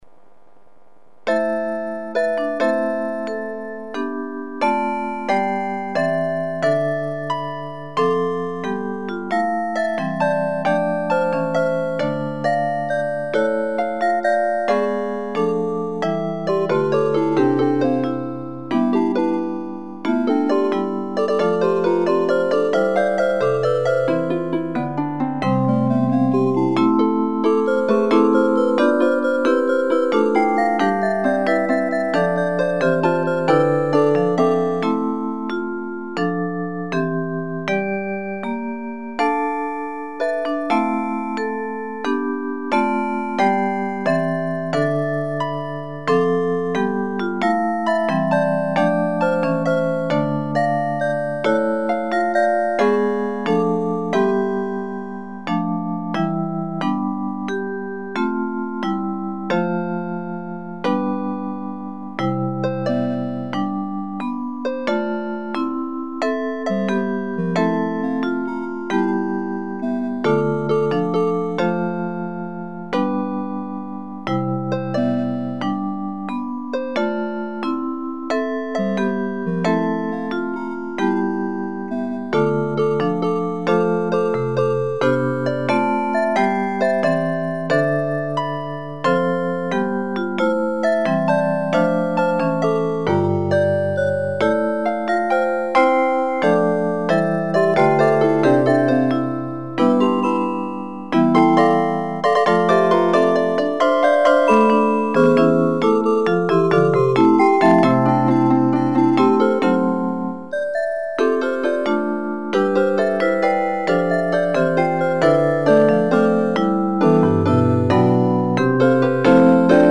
I just added 2 voices to an original piece by Lionel Power, British composer
HYMN MUSIC ; SACRED MUSIC